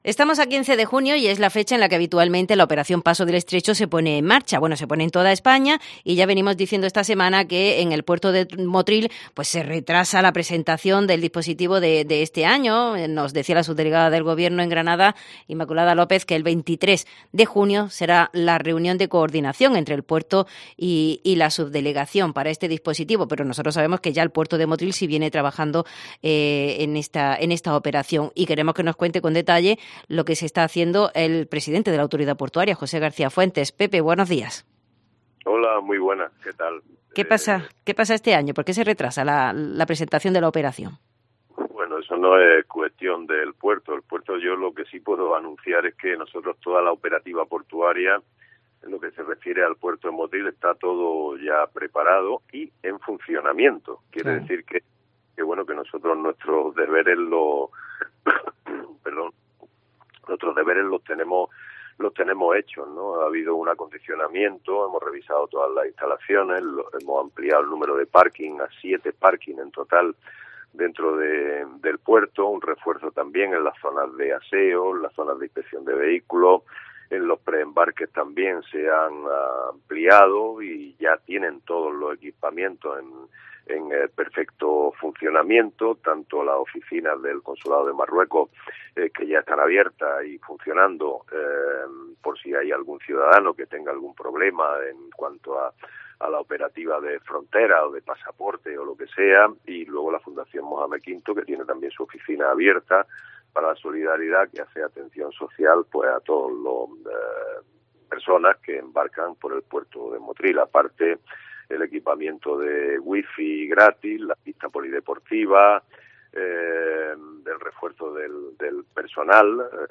Hablamos con su presidente de la Autoridad Portuaria, José García Fuentes,